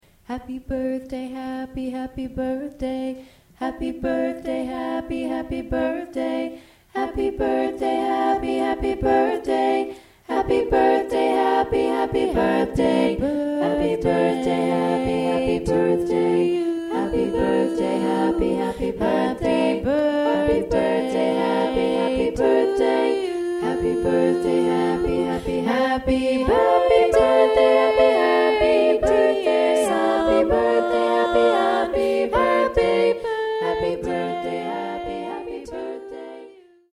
a doo wop version